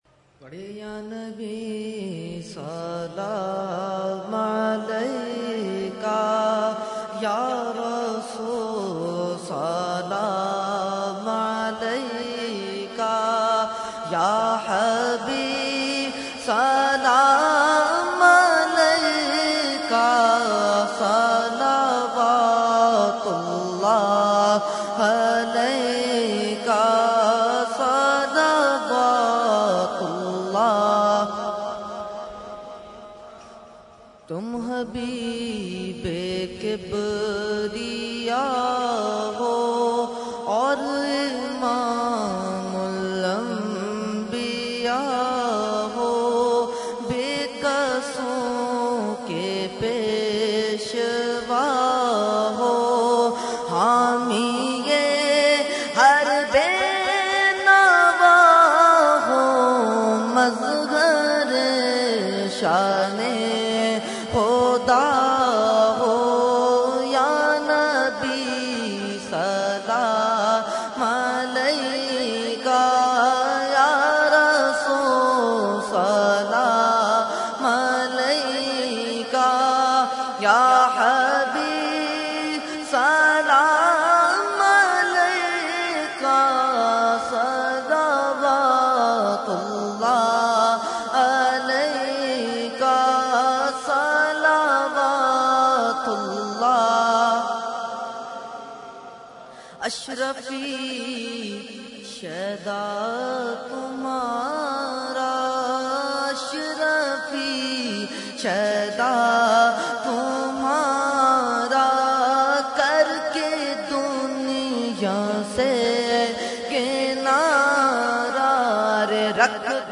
Category : Salam | Language : UrduEvent : Urs Ashraful Mashaikh 2012